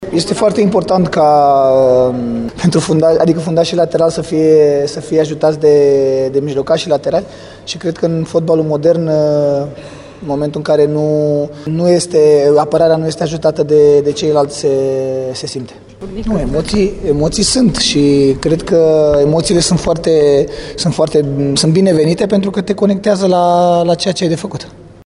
Fotbalistul cu cele mai multe selecţii din actualul lot al României, Răzvan Raţ, vorbeşte despre colaborarea dintre jucătorii de bandă şi de emoţiile de dinaintea primului meci, cel cu Franţa.